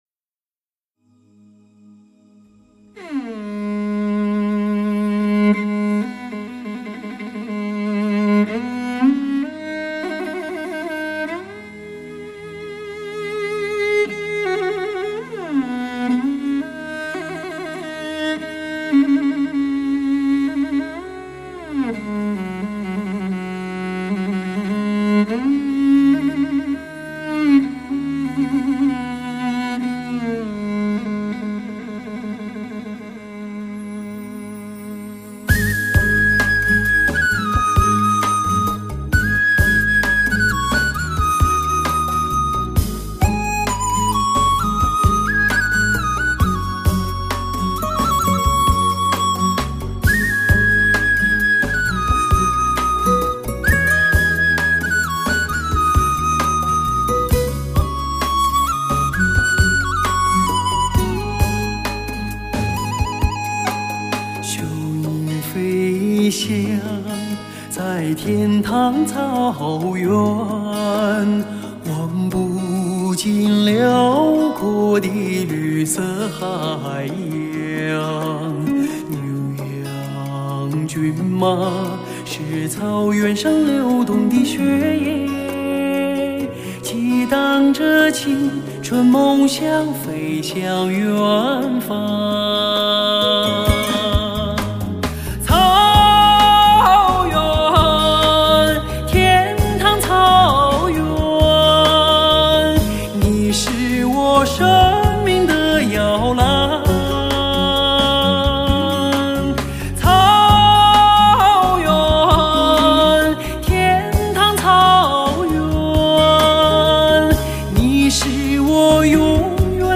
最美的女中音，悠远飘荡的旋律，苍茫里沉静的吟唱……
辽阔绵长的女中音，让您体验前所未有的感染力，